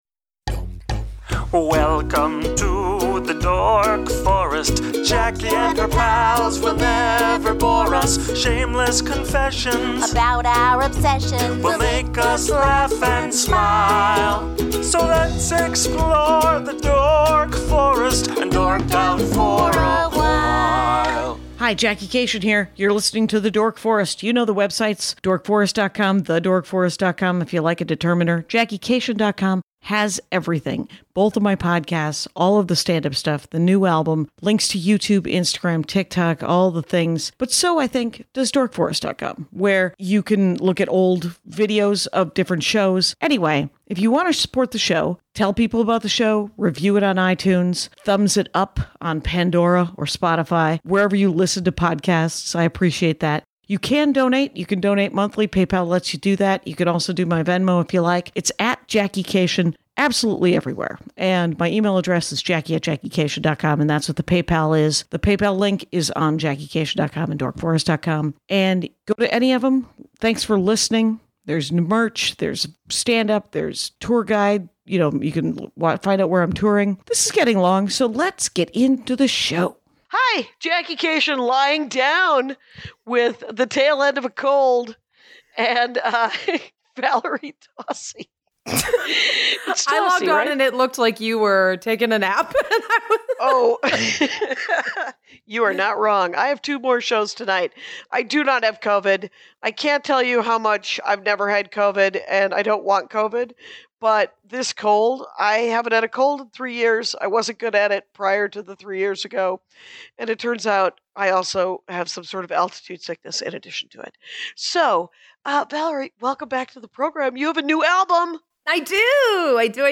I horrify by trying to riff with a scratchy throat.